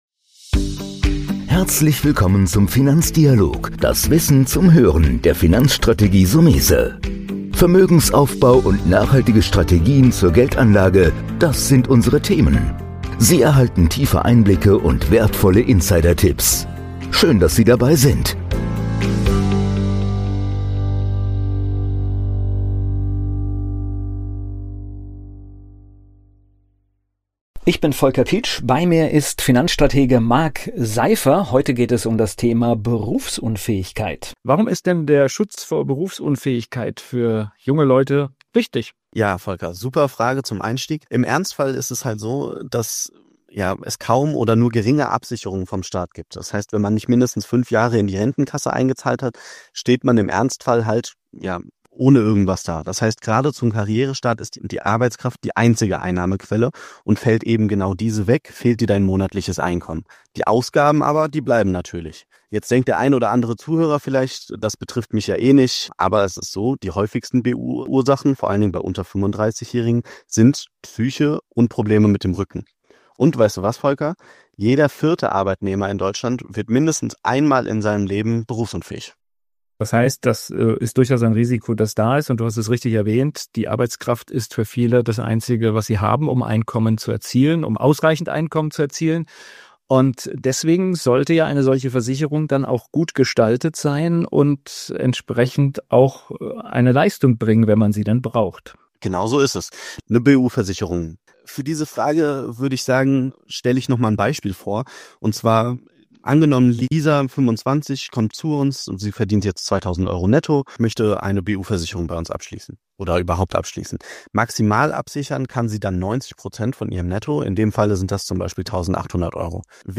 Im Dialog mit unseren Gästen öffnen wir das Fenster zu Wirtschaft, Kapitalmarkt und Finanzwelt. Wir geben tiefe Einblicke und wertvolle Insidertipps.